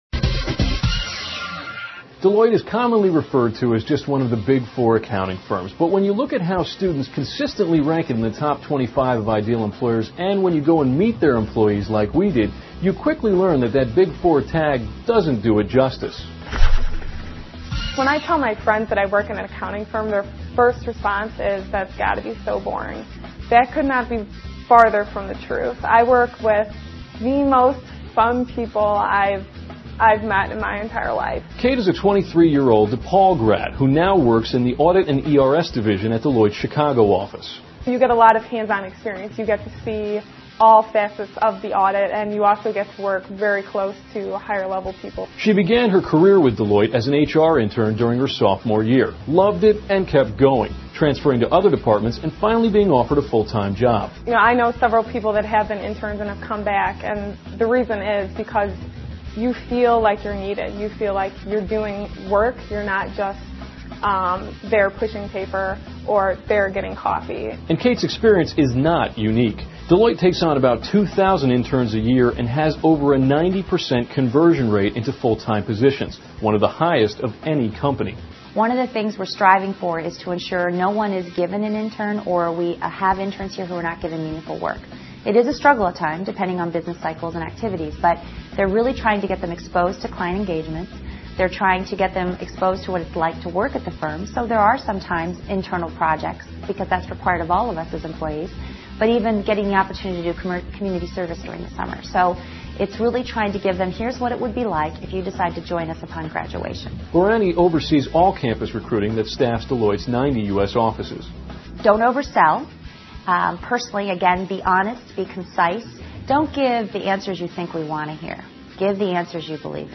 访谈录[Interview]2007-12-17:德勤：美国国防部的审计方 听力文件下载—在线英语听力室